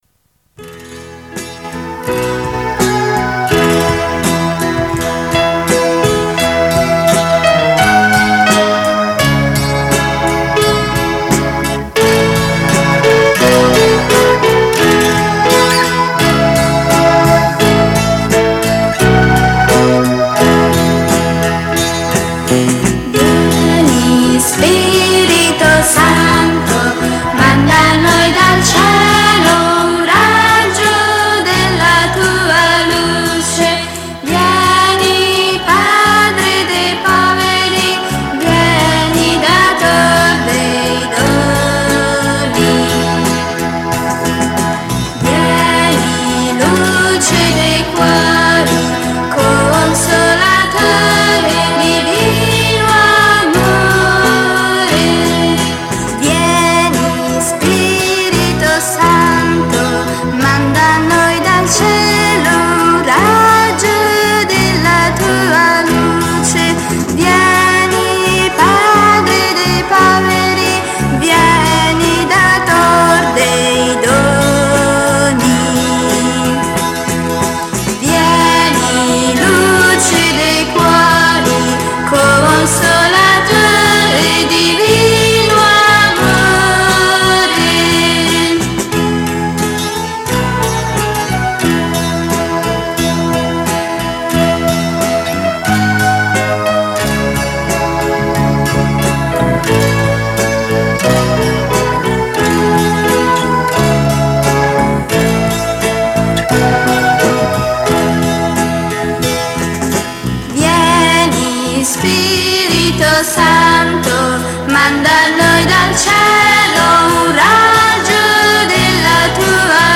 Canto per l’invocazione allo Spirito Santo: